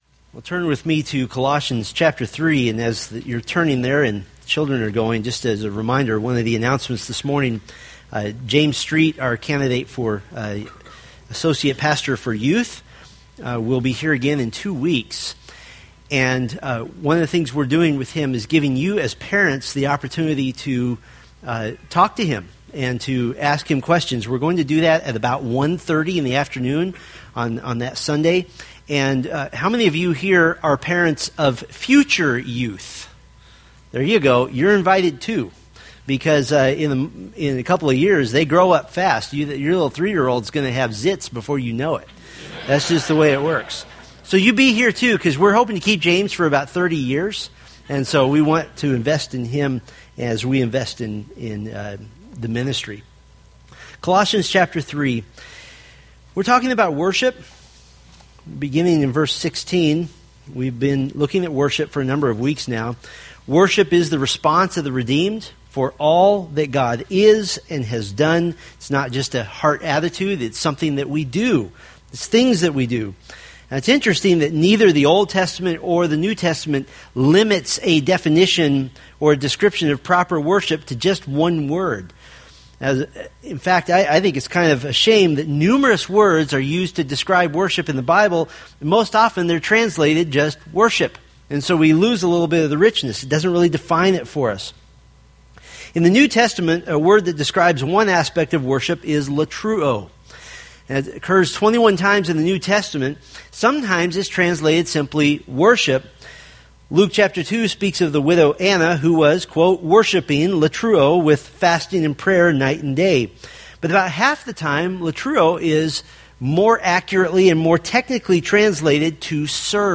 Colossians Sermon Series: Worshiping Christ in Daily Life Download